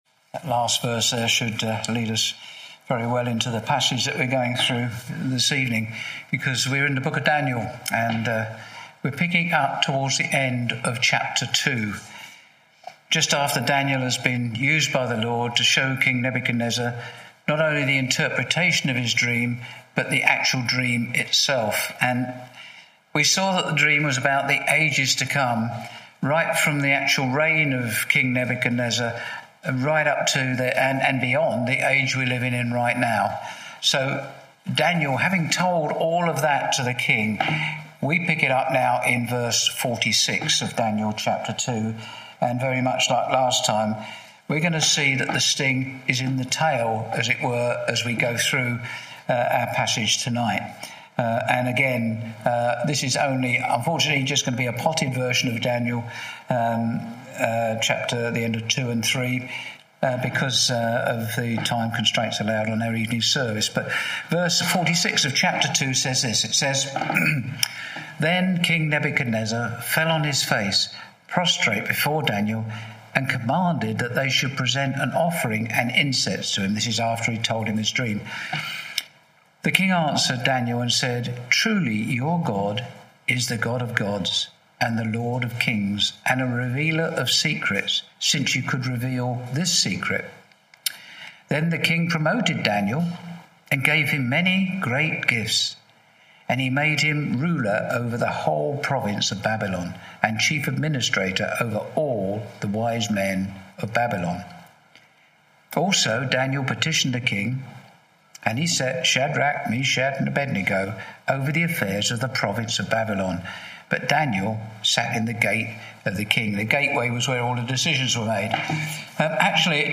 Share this: Share on X (Opens in new window) X Share on Facebook (Opens in new window) Facebook Share on WhatsApp (Opens in new window) WhatsApp Series: Sunday evening studies Tagged with Verse by verse